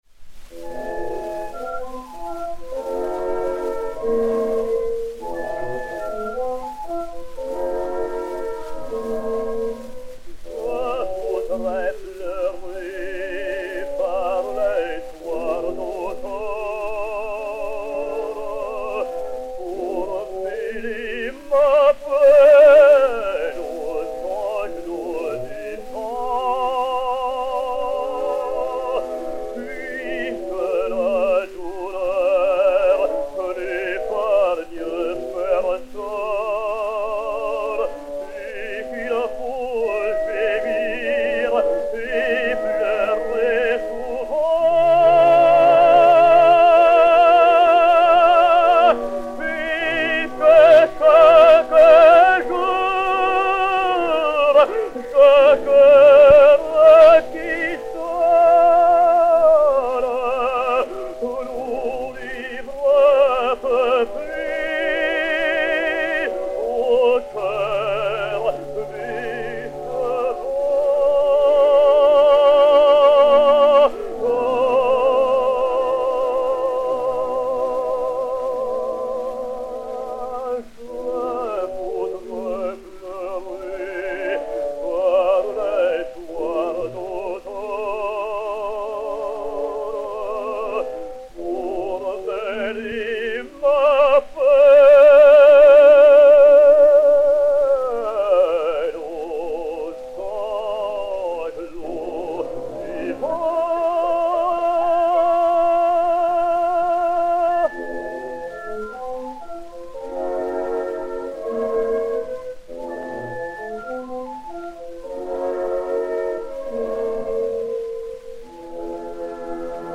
mélodie (par.
Daniel Vigneau et Orchestre